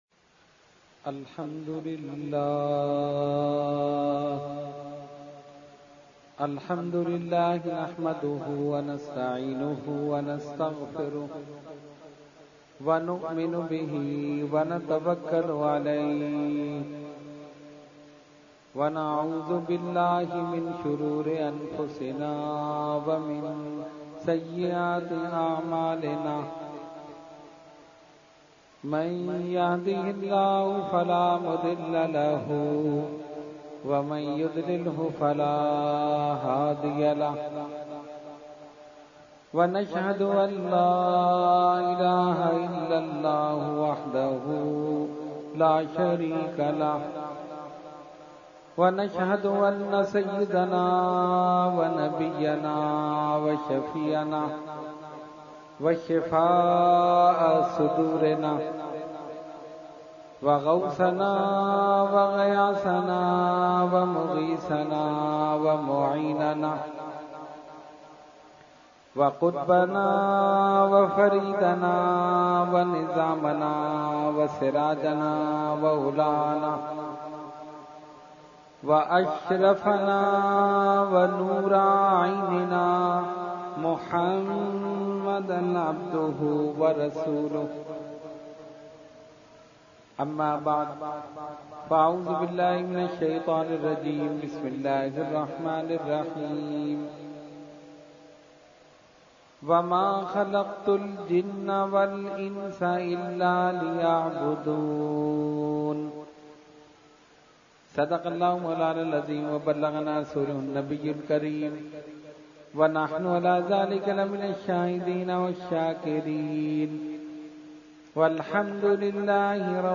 Category : Speech | Language : UrduEvent : Muharram 2017